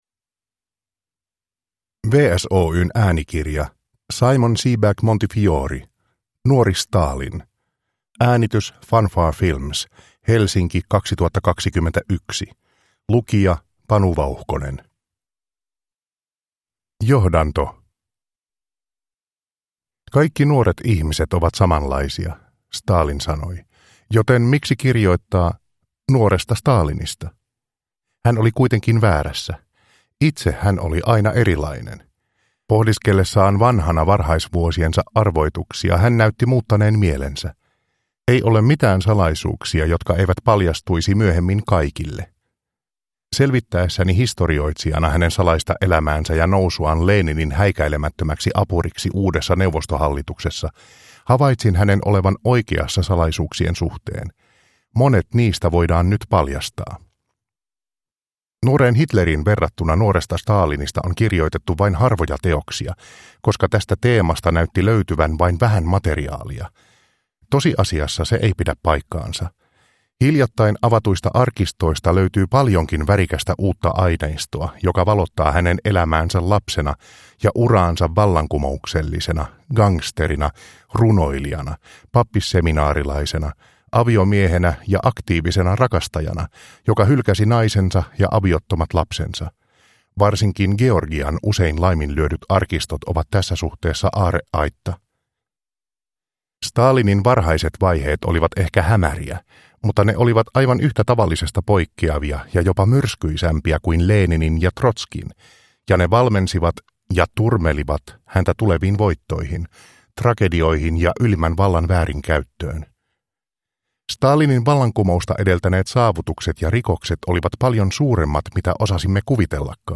Nuori Stalin – Ljudbok – Laddas ner